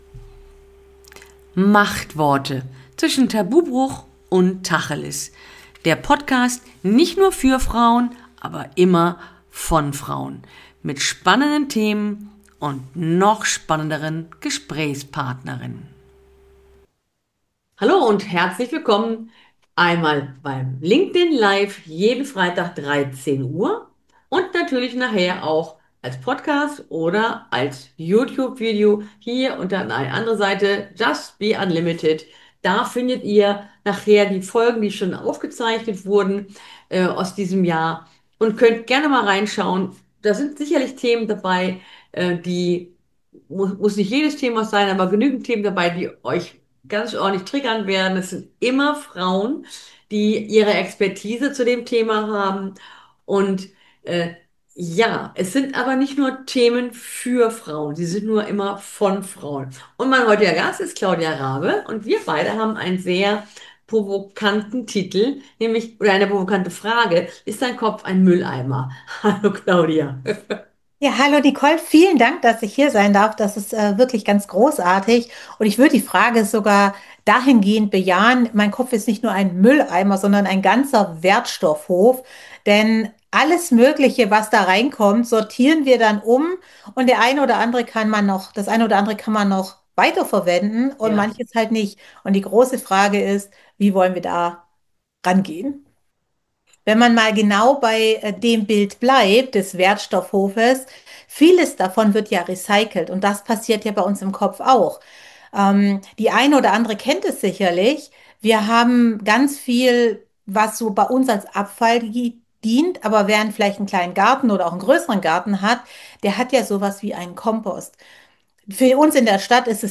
Es wird also sicher sehr spannend, wenn wir uns auf die Spuren der Mafia deiner Essgelüste machen und uns mal die Drahtzieher hinter deinem Heißhunger anschauen Schaut euch unbedingt das neue Video auf YouTube an oder hört das Gespräch hier als Podcast.